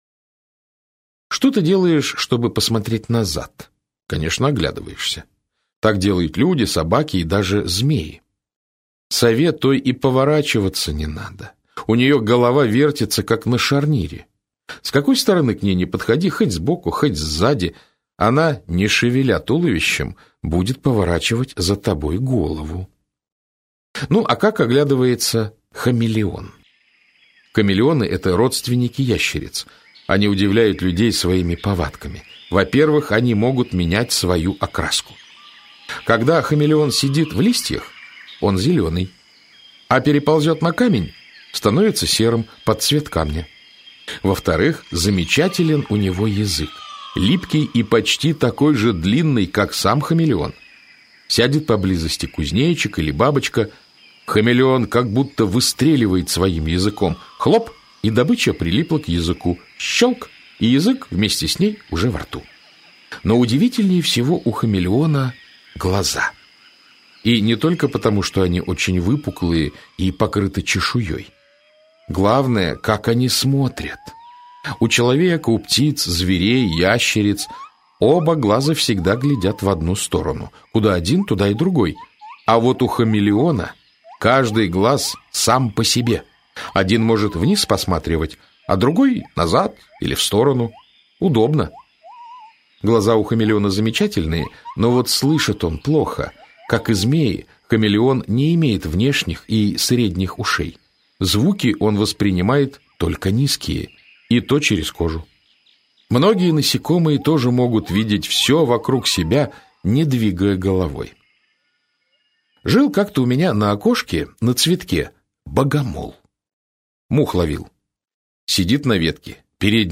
Аудиокнига Сколько глаз у стрекозы?
Автор Виталий Танасийчук Читает аудиокнигу Александр Клюквин.